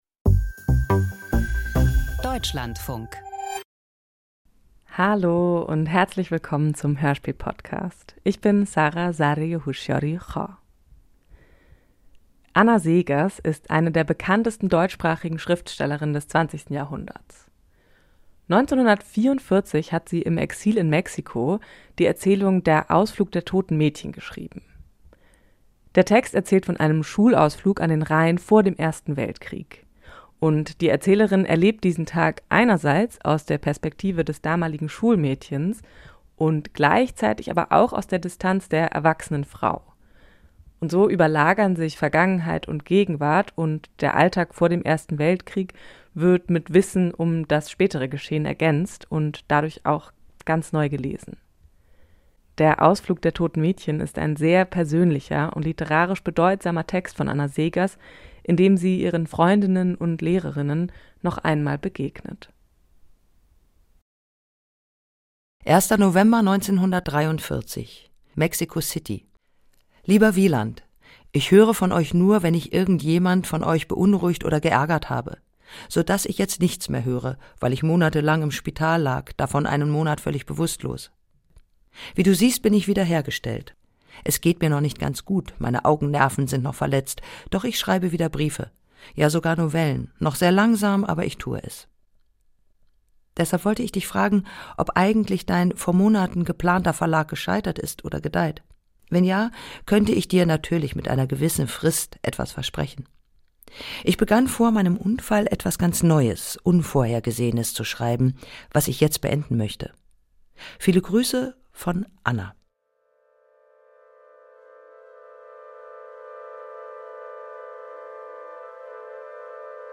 Hörspiel nach Anna Seghers’ Erzählung - Der Ausflug der toten Mädchen